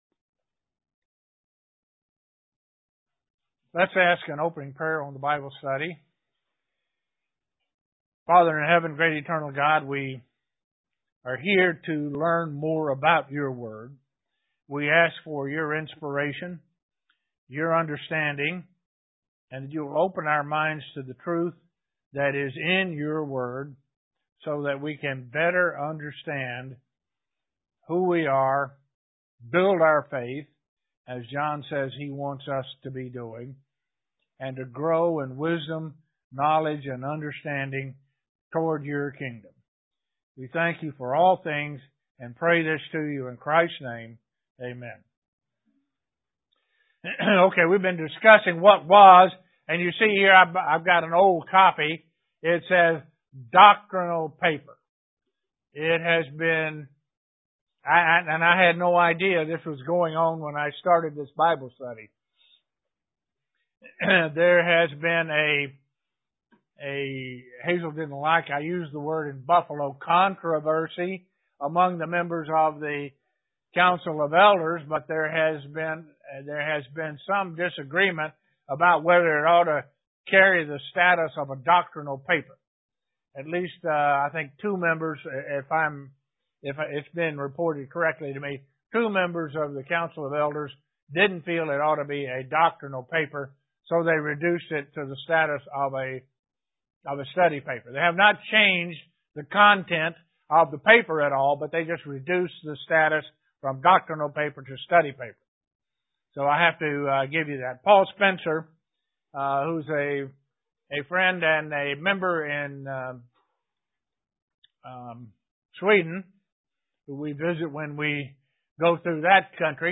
Bible Study Final part of the Last Great Day Bible study